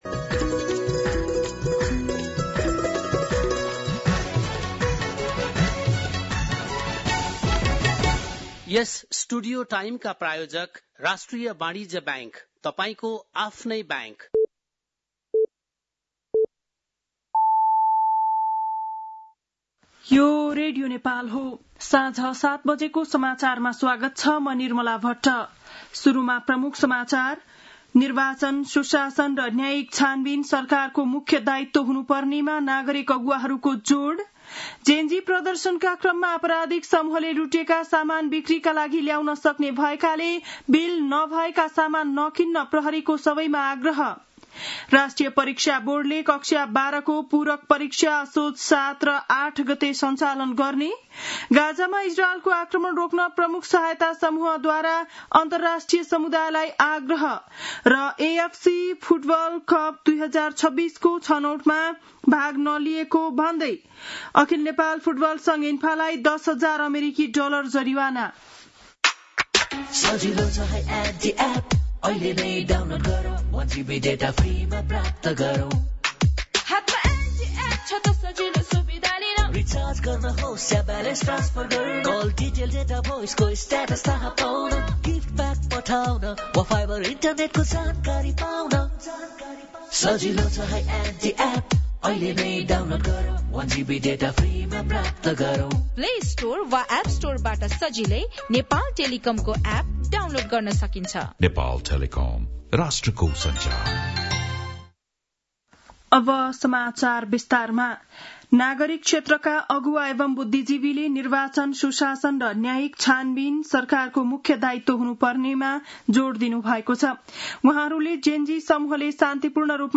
बेलुकी ७ बजेको नेपाली समाचार : १ असोज , २०८२
7-pm-nepali-news-6-01.mp3